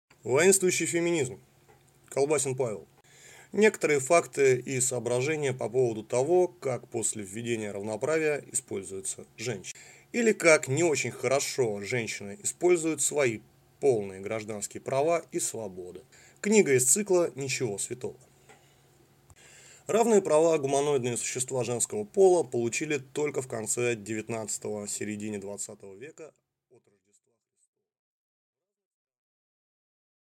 Аудиокнига Воинствующий феминизм | Библиотека аудиокниг